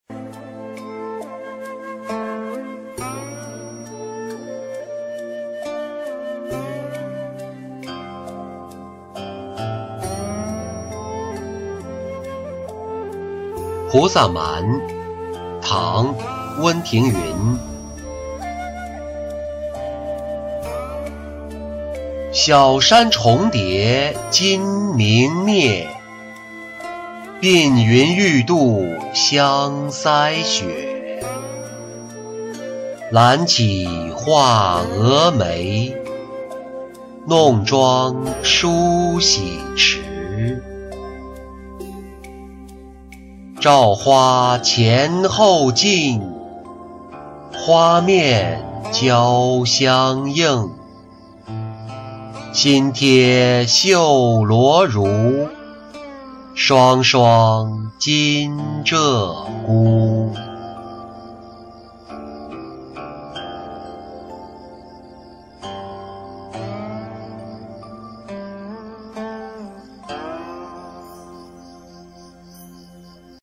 菩萨蛮·小山重叠金明灭-音频朗读